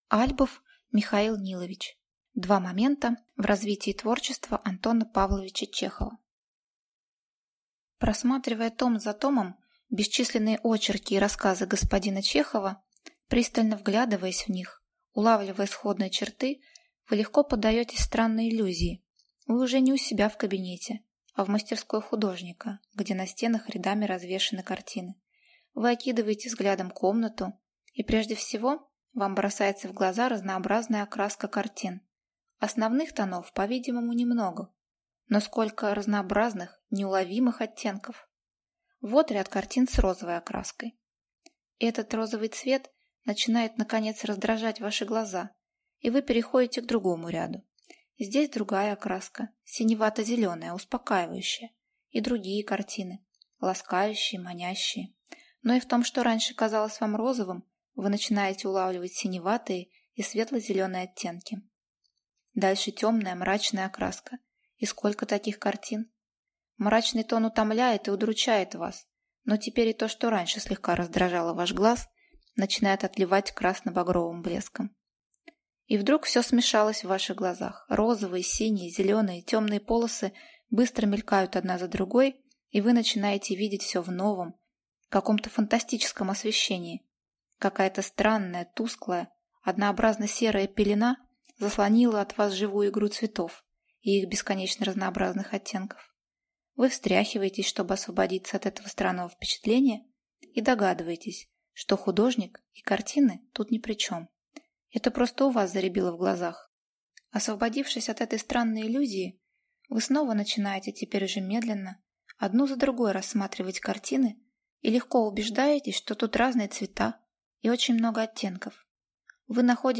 Аудиокнига Два момента в развитии творчества Антона Павловича Чехова | Библиотека аудиокниг